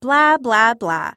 Звуки передразнивания
Женщина закрыла уши и говорит бла бла